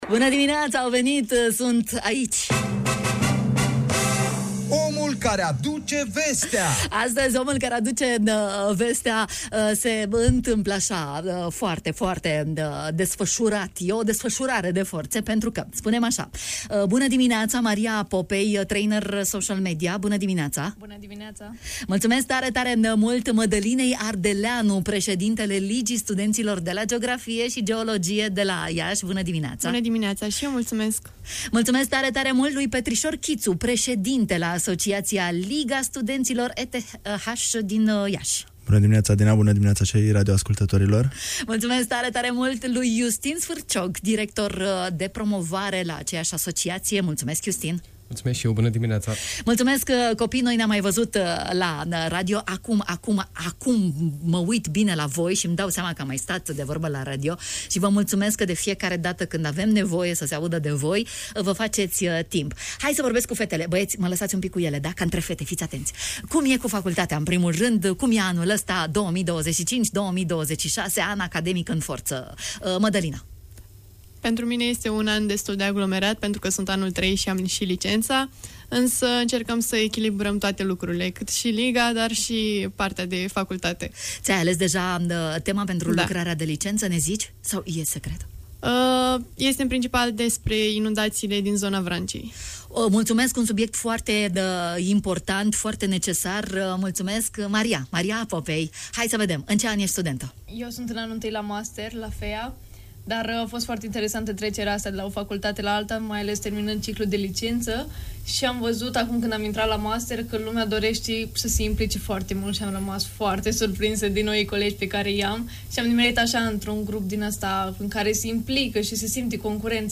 Vreau O Țară Ca Afară (VOTCA) și ”România la Pas”, proiectele despre care s-a auzit astăzi la Radio România Iași.